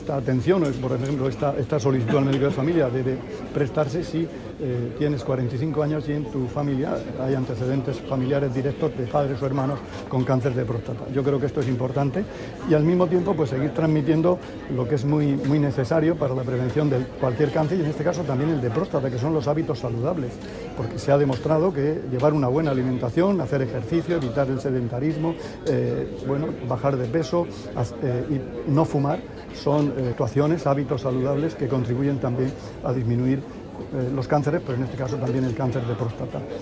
Sonido/ Declaraciones del consejero de Salud sobre prevención del cáncer de próstata